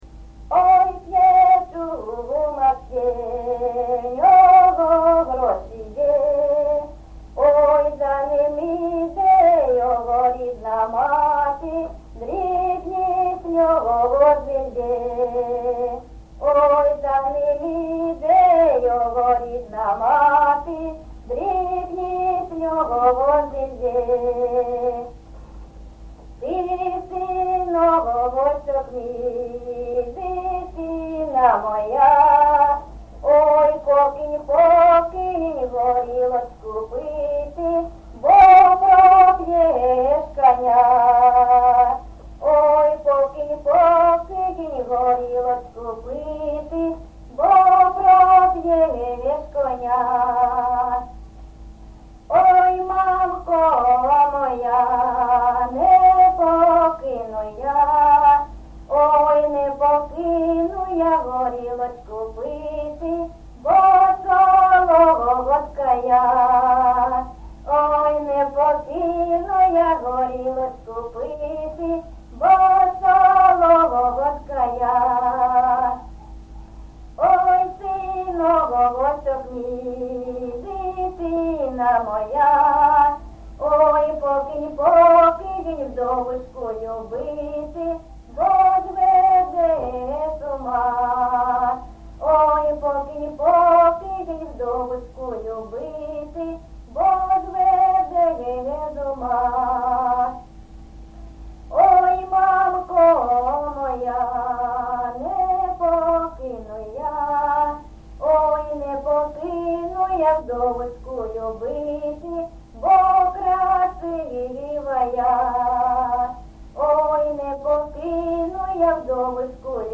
Місце записус-ще Троїцьке, Сватівський район, Луганська обл., Україна, Слобожанщина